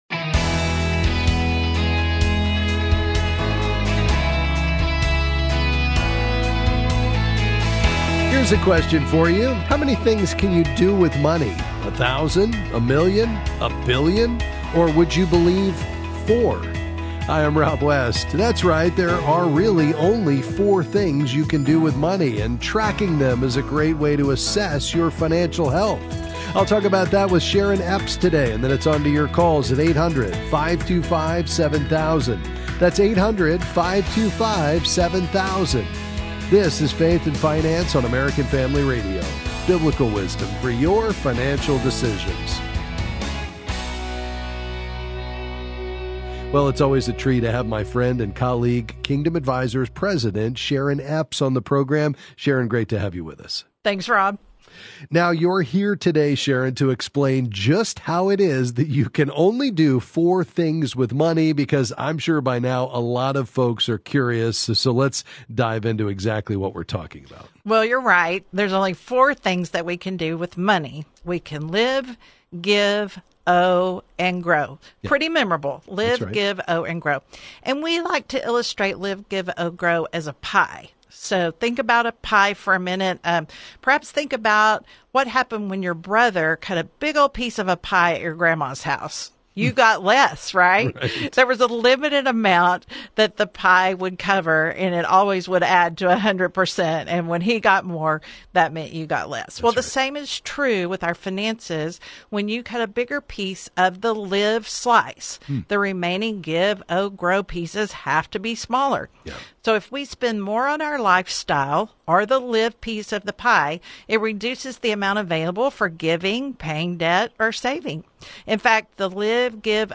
Then they’ll answer your calls and questions.